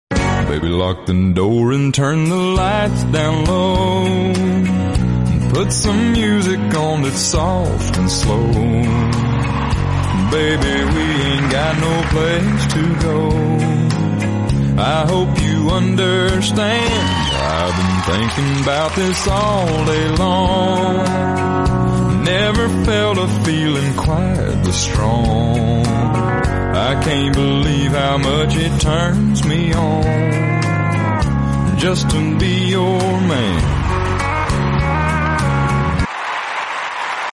Deep Sexy Country Voice